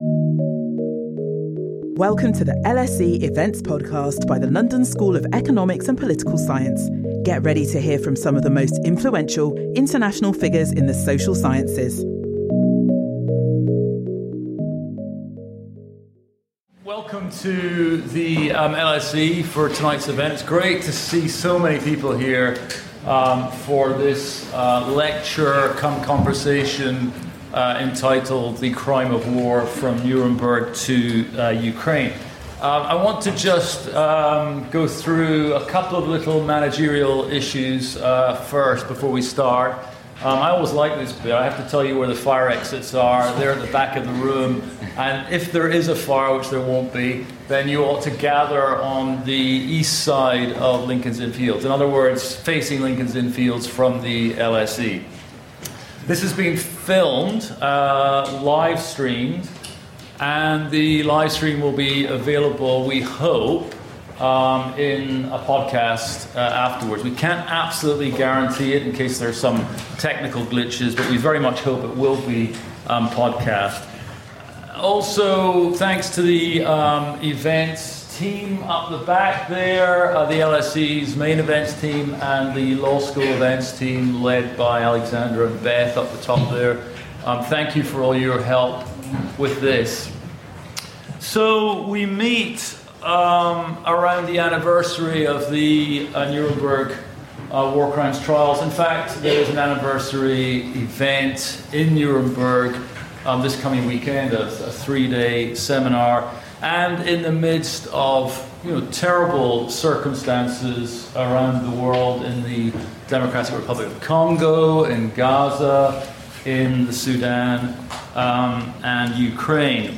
The lecture explores the ethical complexities around potential solutions like boosting fertility, delaying retirement, and increasing skilled migration.